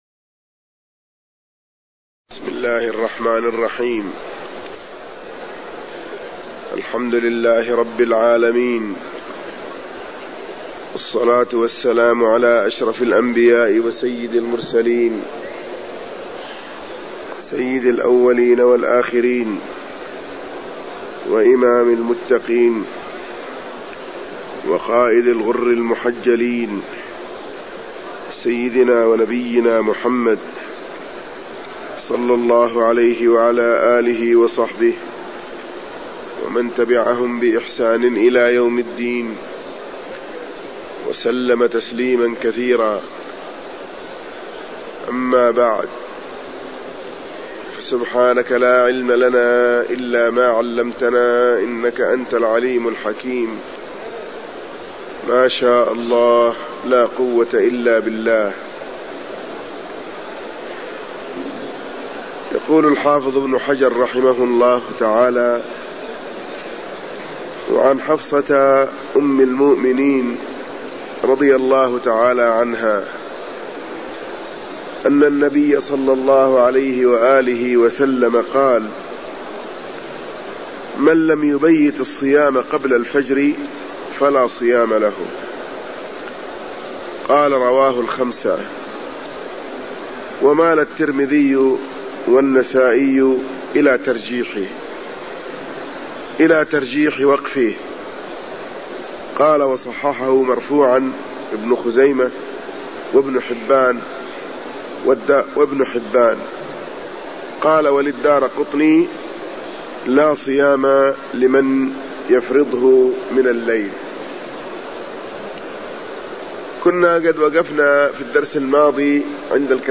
درس في الصيام
المكان: المسجد النبوي الشيخ